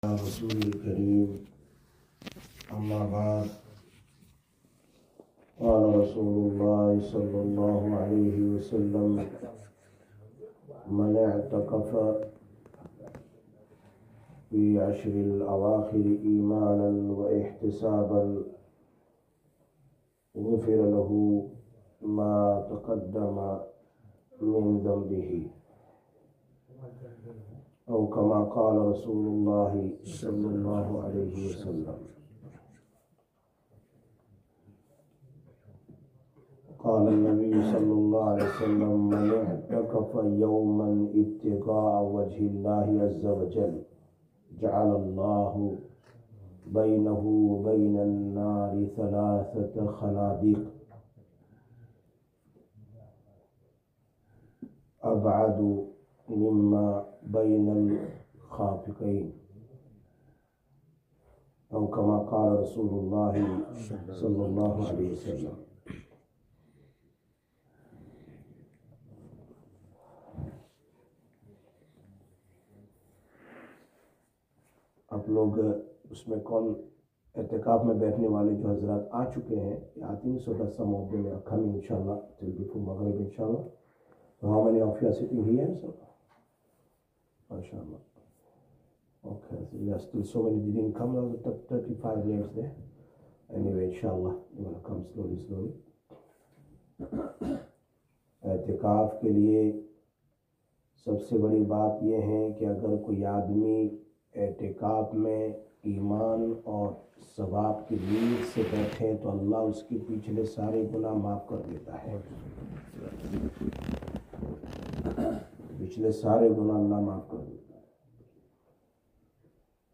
20-03-25 Masjid Quba Ehtikaaf Masail